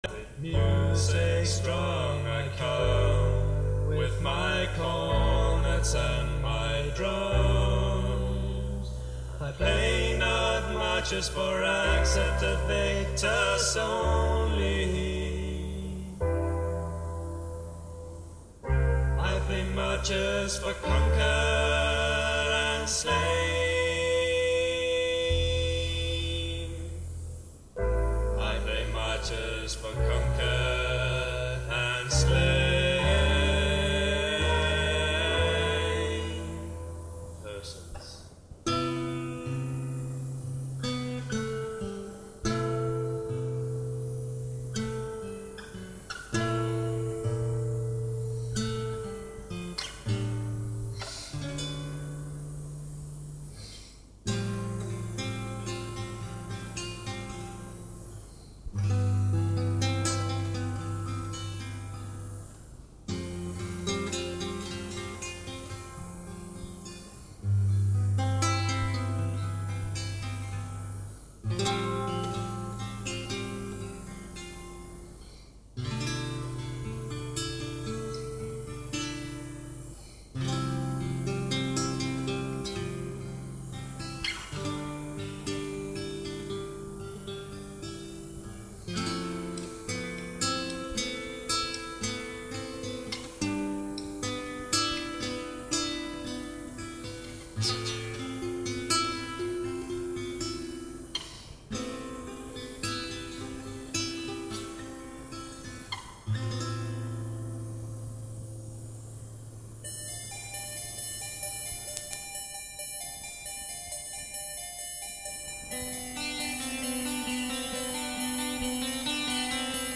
flute and saxophone